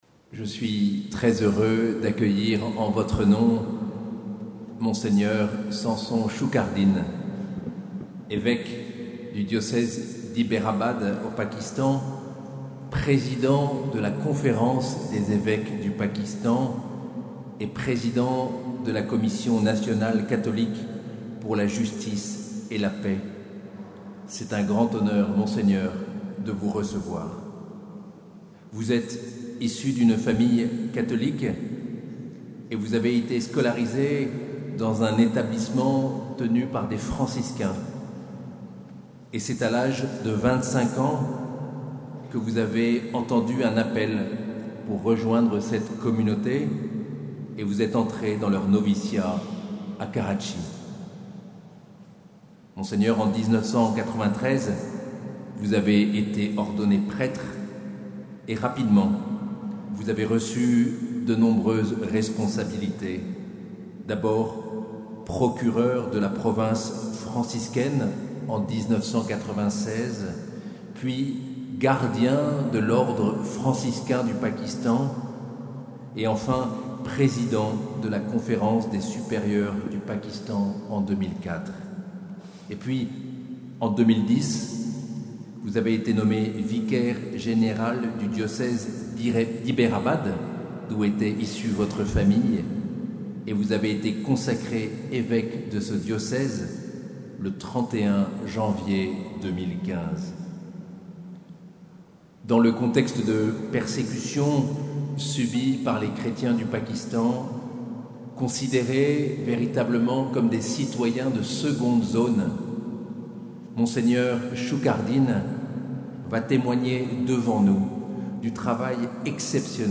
Nuit des Témoins le 21 novembre 2024 - Cathédrale de Bayonne
Mgr Samson SHUKARDIN, évêque d’Hyderabad et président de la Commission Nationale Justice et Paix au Pakistan.